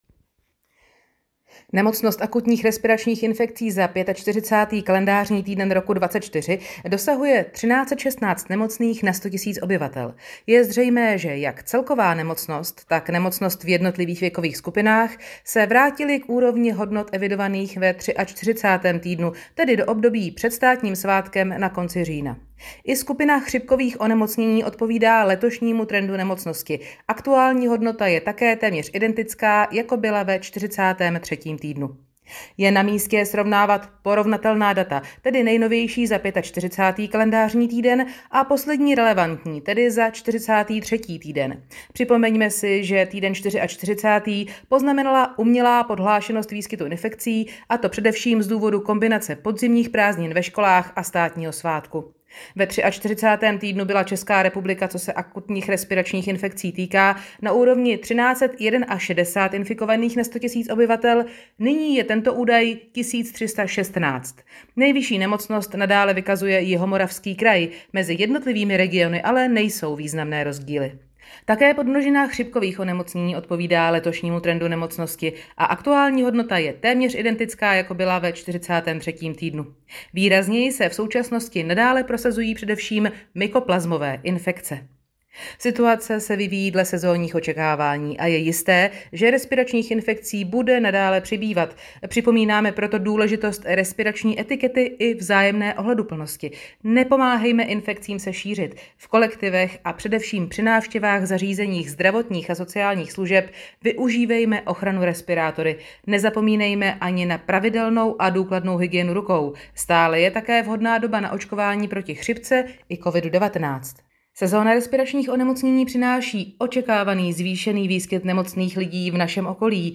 Audio verze tiskové zprávy 45. KT Podrobná zpráva ARI/ILI 45.